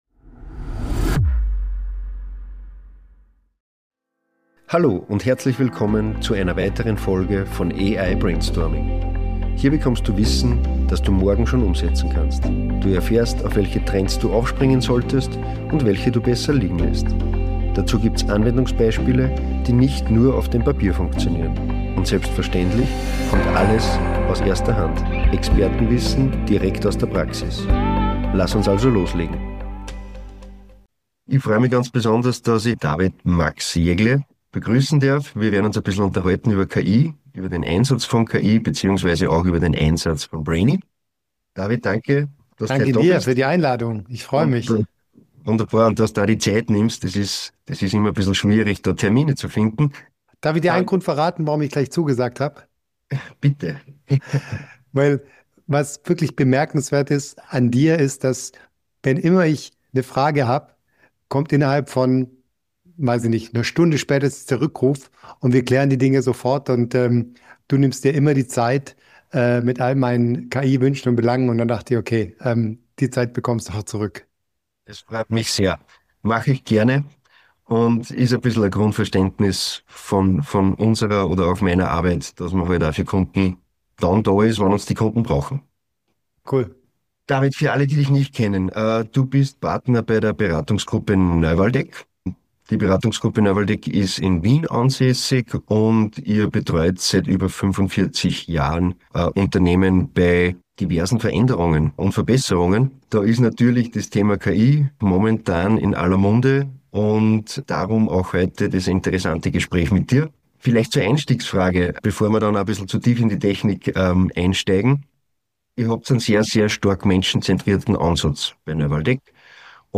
Wenn KI auf Organisationsentwicklung trifft: Ein Gespräch über echten Mehrwert.
Ein Gespräch ohne Skript, dafür mit Tiefgang.